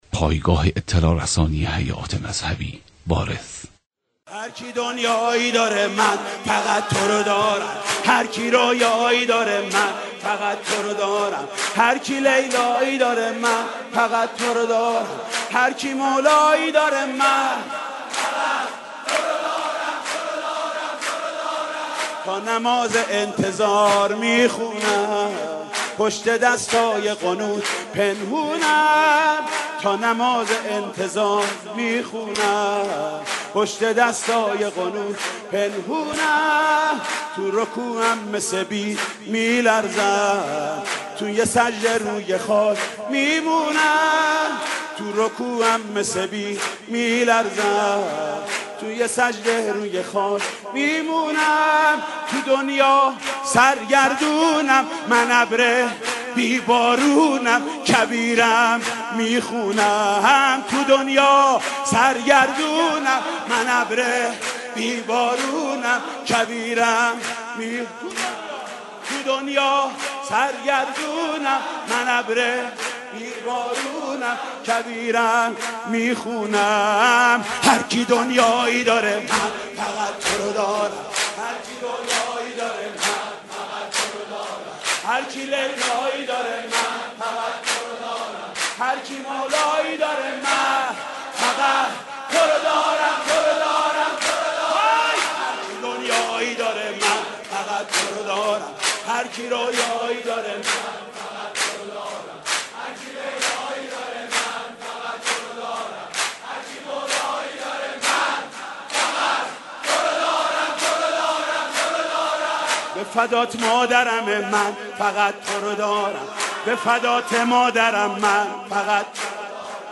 مولودی حاج محمود کریمی به مناسبت میلاد با سعادت امام زمان (عج)